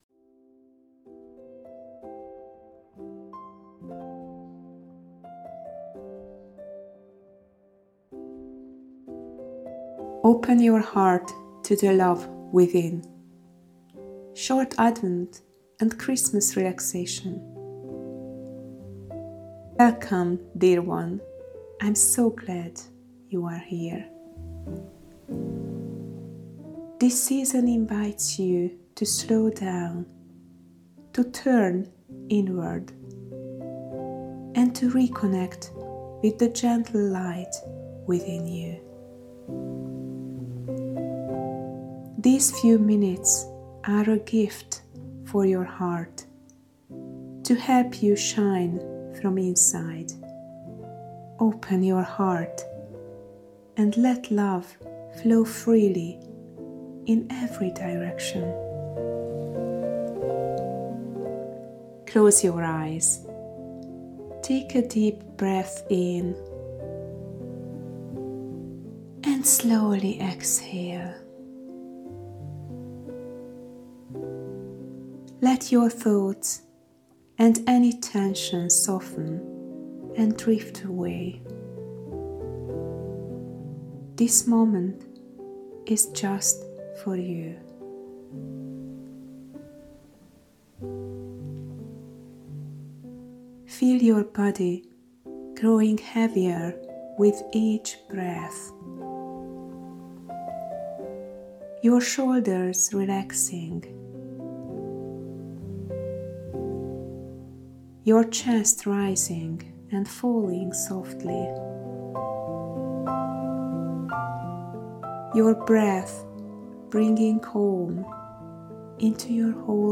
Personalised guided relaxation
You hear only my voice gently guiding you, as your body and mind relax and you reconnect with your inner peace. My personalised audio recordings blend elements of autogenic training with intuitive guidance, created to resonate with your current needs.